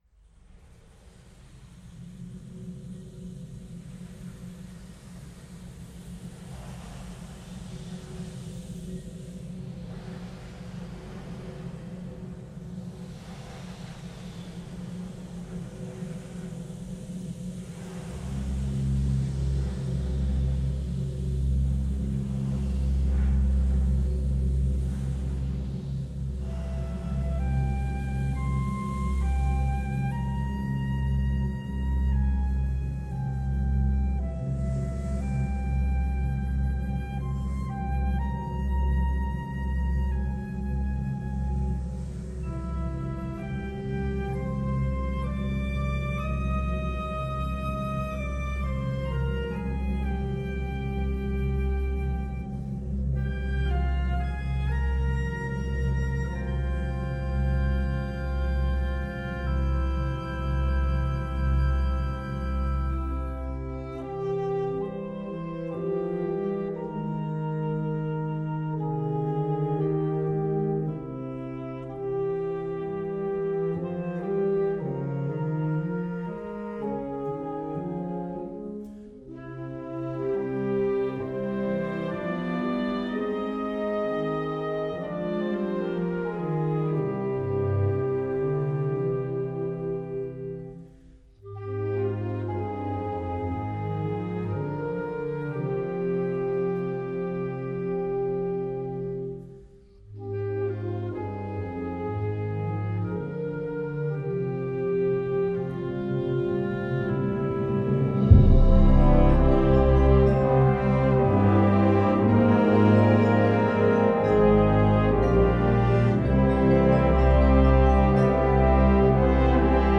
Categorie Harmonie/Fanfare/Brass-orkest
Subcategorie Hedendaagse muziek (1945-heden)
Bezetting Ha (harmonieorkest)